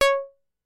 Roland Juno 6 Pulse Bass " Roland Juno 6 Pulse Bass C6 ( Pulse Bass8564)
Tag: MIDI-速度-32 CSharp6 MIDI音符-85 罗兰朱诺-6 合成器 单票据 多重采样